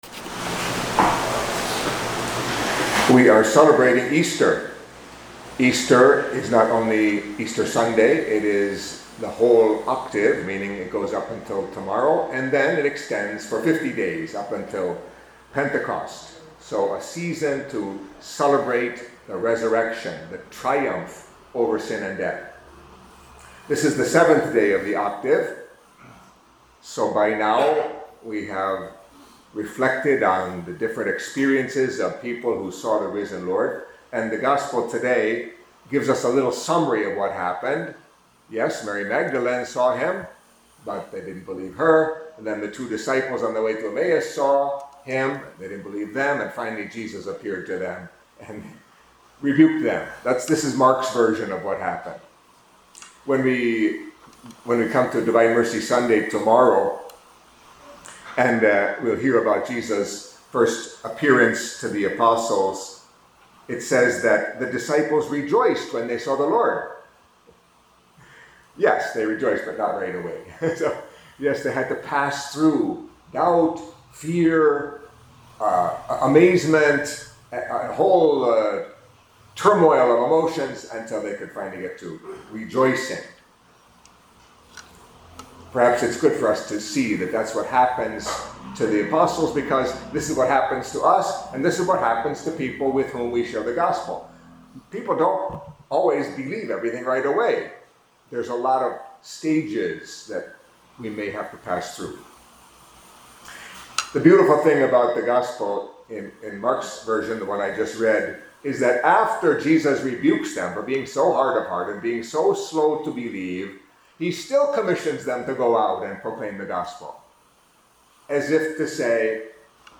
Catholic Mass homily for Saturday in the Octave of Easter